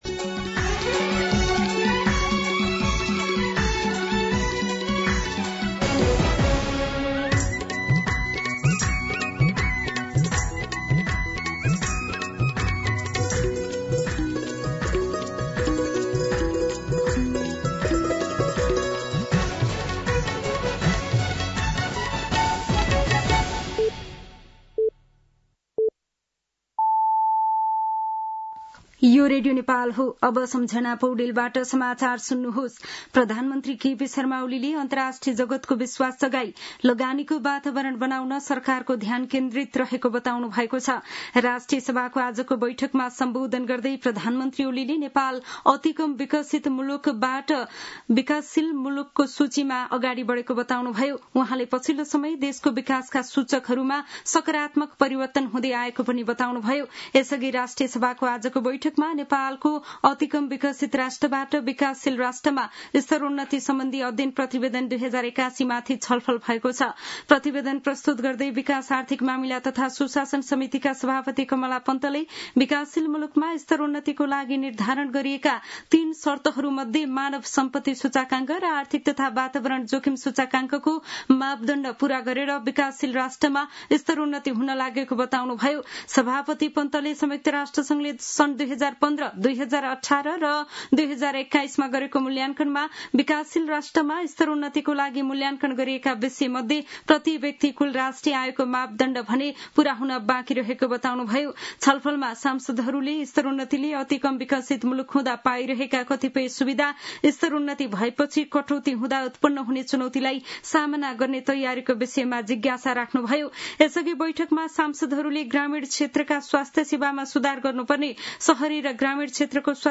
दिउँसो ४ बजेको नेपाली समाचार : १४ साउन , २०८२